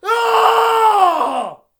battle-cry-2.mp3